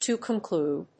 アクセントto conclúde